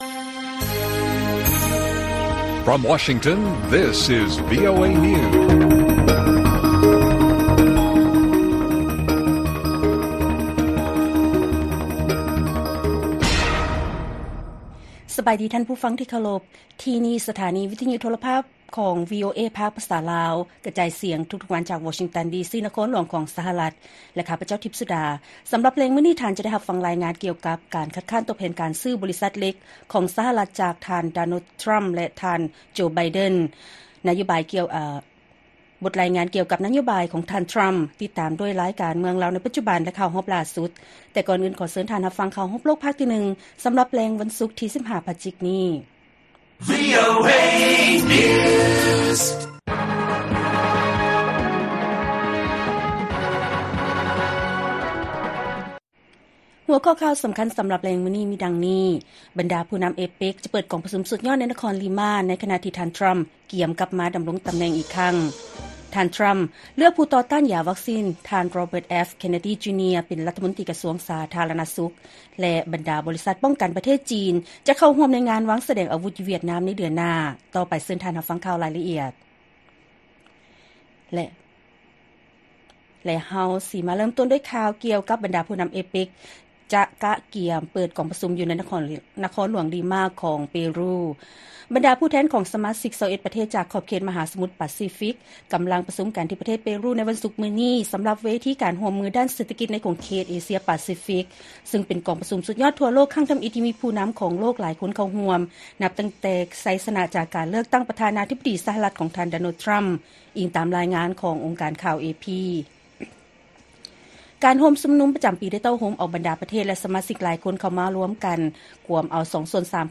ລາຍການກະຈາຍສຽງຂອງວີໂອເອລາວ: ບັນດາຜູ້ນໍາ APEC ຈະເປີດກອງປະຊຸມສຸດຍອດໃນນະຄອນລິມາ ໃນຂະນະທີ່ ທ່ານ ທຣໍາ ກຽມກັບມາດໍາລົງຕໍາແໜ່ງ